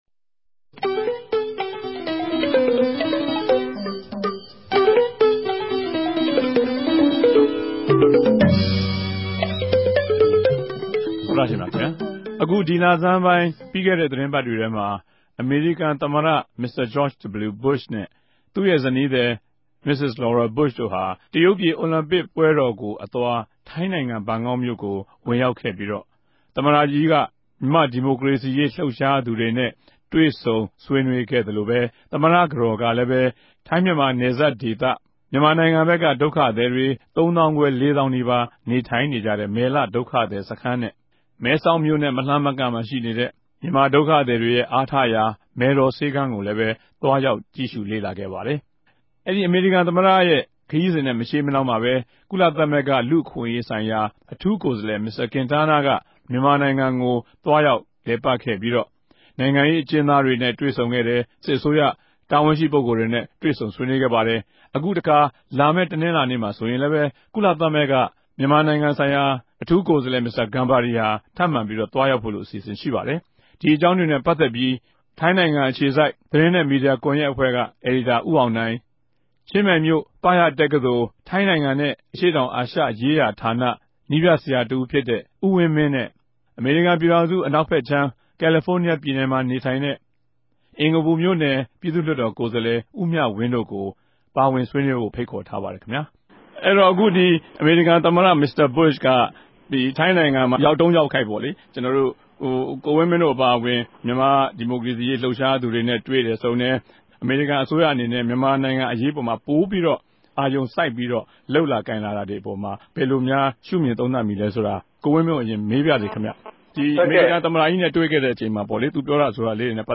ူမန်မာိံိုင်ငံအရေးနဲႛ ပတ်သက်္ဘပီး အမေရိကန် သမတ မင်္စတာ ဘုြရ်နဲႛ ဇနီးသည်တိုႛရဲႛ ဋ္ဌကိြးပမ်းမနြဲႛ ကုလသမဂ္ဂအထူး ကိုယ်စားလြယ်တေရြဲႛ ဋ္ဌကိြးပမ်းလြပ်ရြားမြတြေ အေုကာင်းကို အေူခခ္ဘံပီး ဆြေးေိံြးထားုကပၝတယ်။